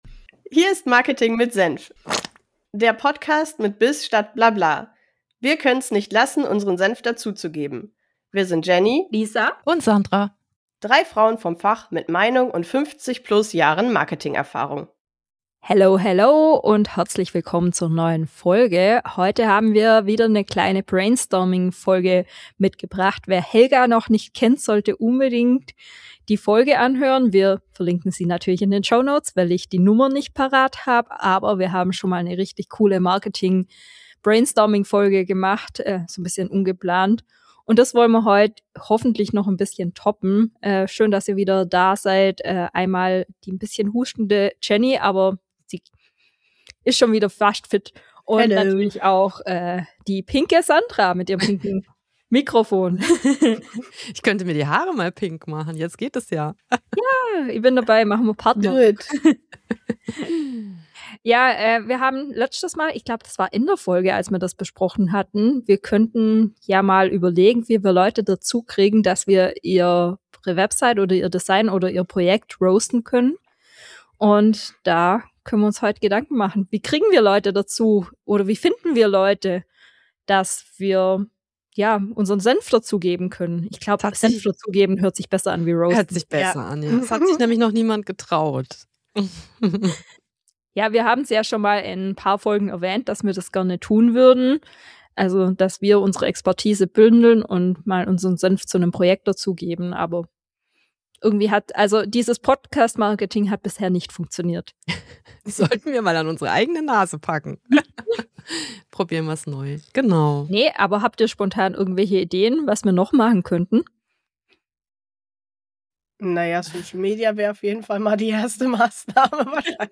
Wir haben lange drüber geredet – jetzt machen wir’s. In dieser Folge brainstormen wir live, wie wir künftig unseren Senf zu eurem Marketing dazugeben können: kostenlos, ehrlich und konstruktiv.